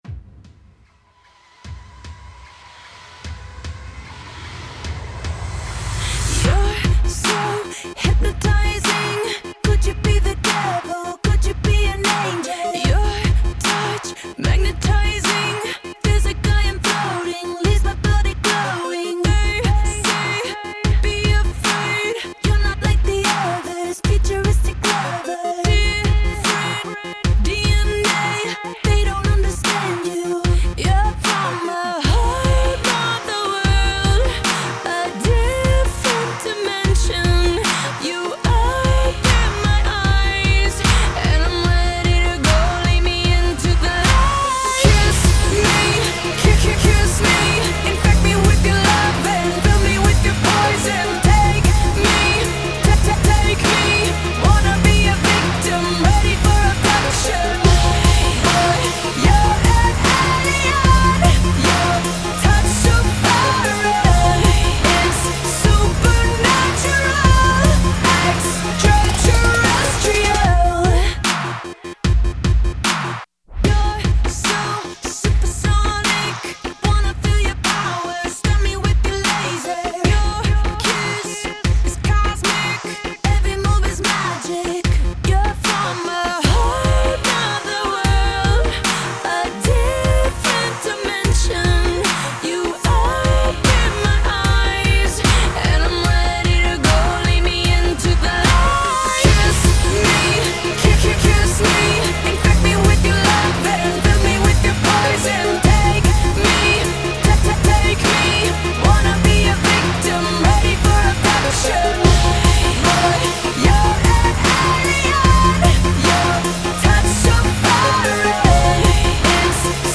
Категория: Поп